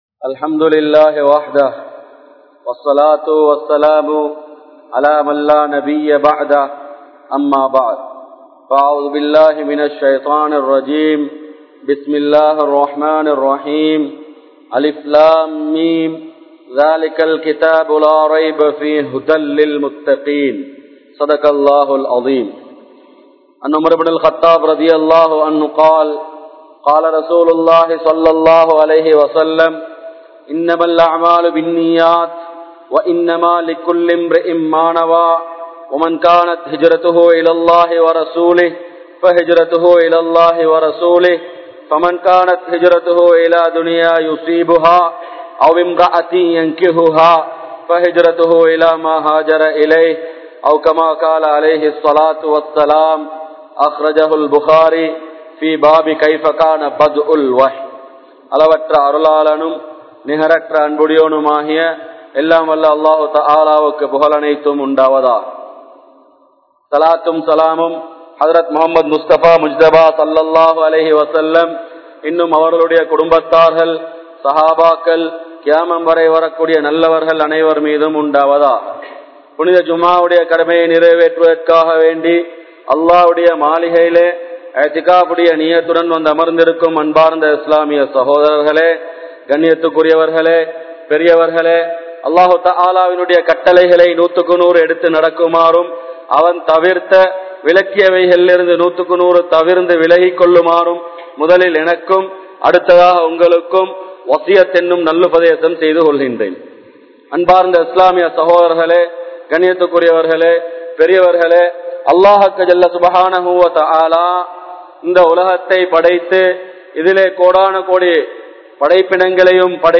Kudumba Vaalkai (குடும்ப வாழ்க்கை) | Audio Bayans | All Ceylon Muslim Youth Community | Addalaichenai
Colombo 12, Aluthkade, Muhiyadeen Jumua Masjidh